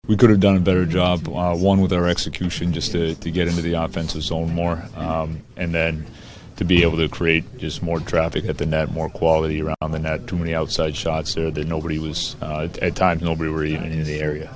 Coach Dan Muse says the Pens weren’t busy enough in front of the Bruins’ net.